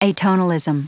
Prononciation du mot atonalism en anglais (fichier audio)
atonalism.wav